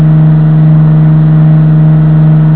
flaps-stereo.wav